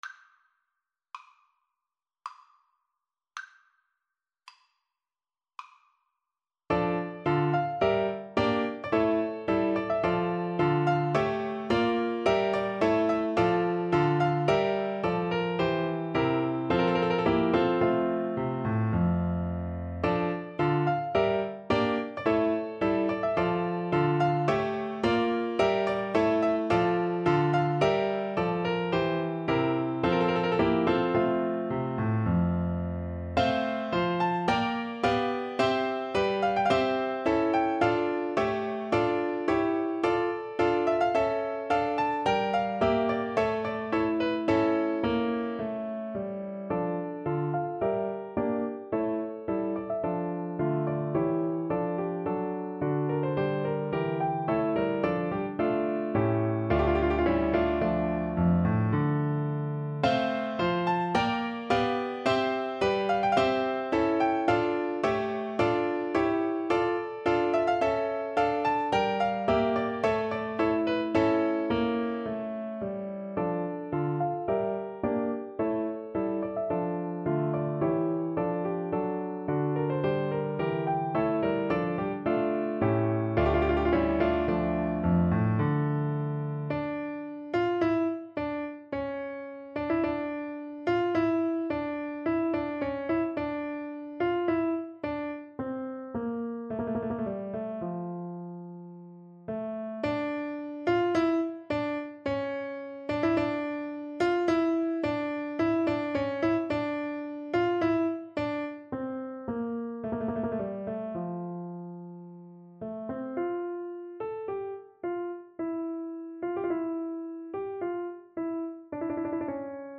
Play (or use space bar on your keyboard) Pause Music Playalong - Piano Accompaniment Playalong Band Accompaniment not yet available reset tempo print settings full screen
D minor (Sounding Pitch) (View more D minor Music for Recorder )
3/4 (View more 3/4 Music)
~ = 54 Moderato
Classical (View more Classical Recorder Music)